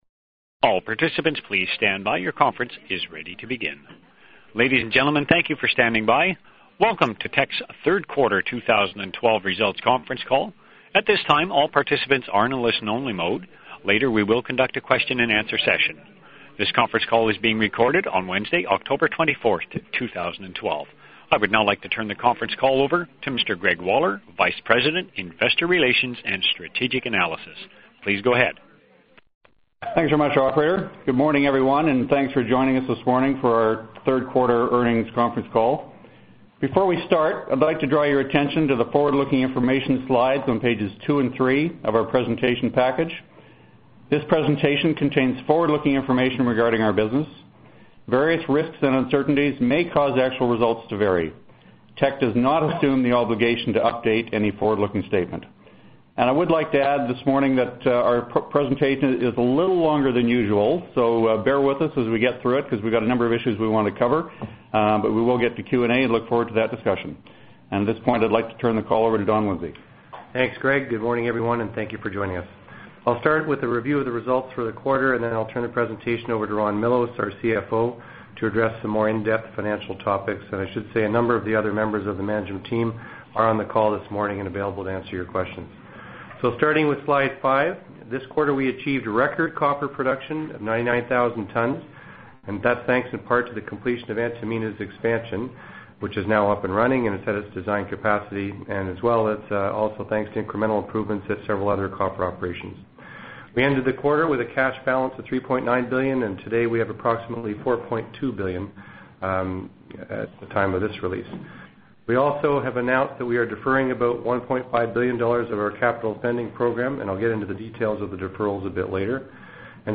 Q3 2012 Financial Report Conference Call Audio File